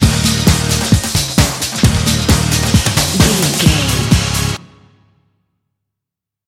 Uplifting
Ionian/Major
D
drum machine
synthesiser
bass guitar